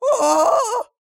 尖叫呼喊大叫 1 " 男性尖叫 7
Tag: 尖叫 恐惧 大叫 痛苦 大呼小叫 疼痛